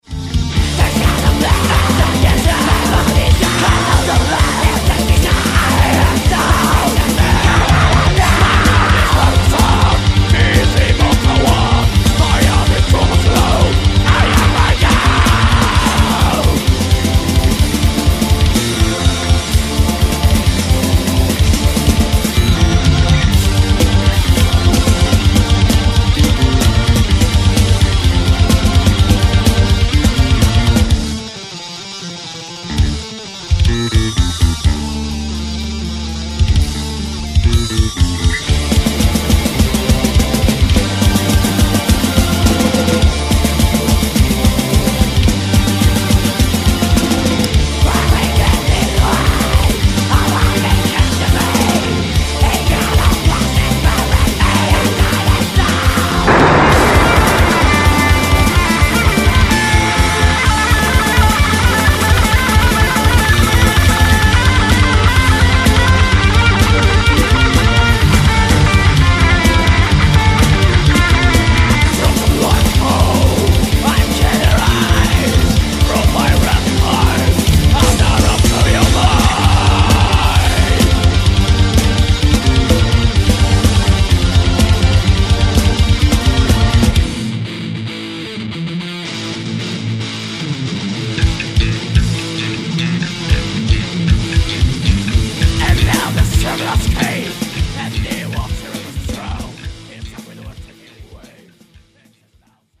*Genre: Melodic Black Metal